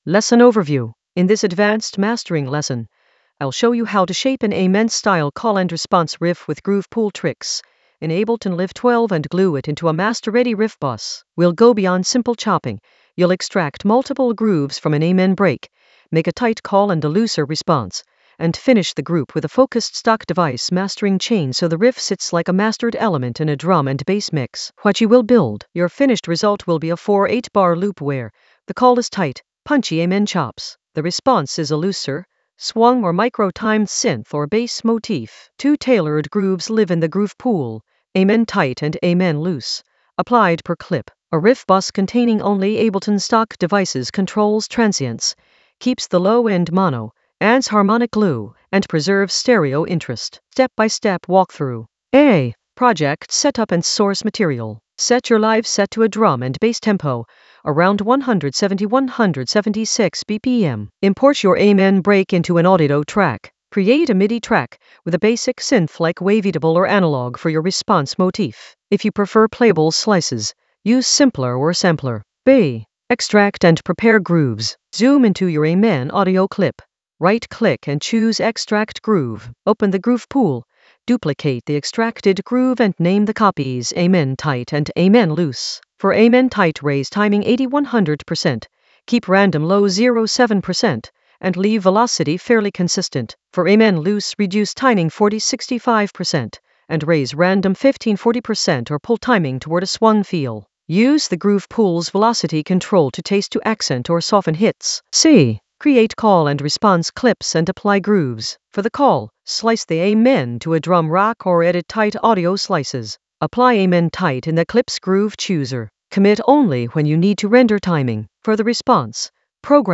An AI-generated advanced Ableton lesson focused on Shape an Amen-style call-and-response riff with groove pool tricks in Ableton Live 12 in the Mastering area of drum and bass production.
Narrated lesson audio
The voice track includes the tutorial plus extra teacher commentary.